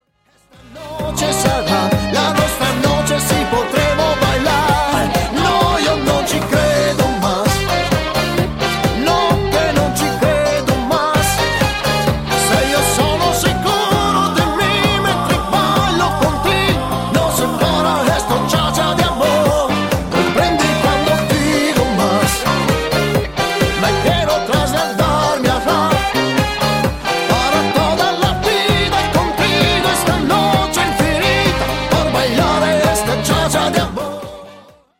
CHA CHA CHA  (3.37)